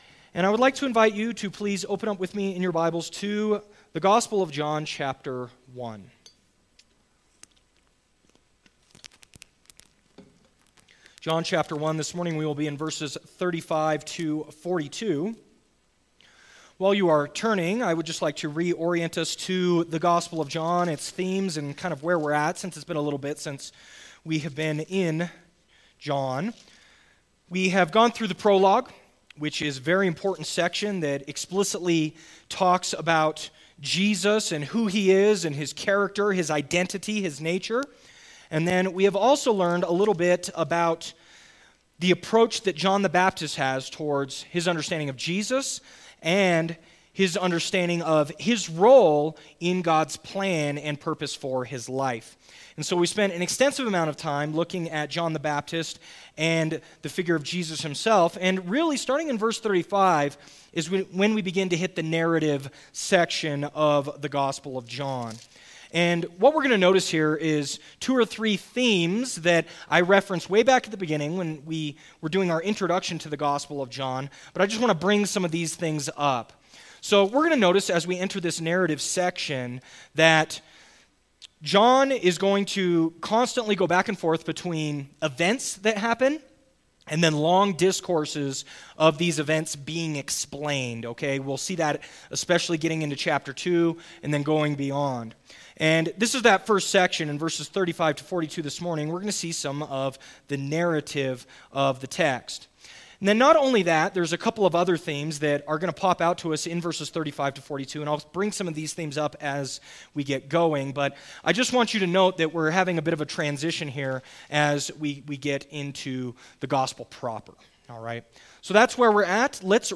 Sermons | First Baptist Church of Leadville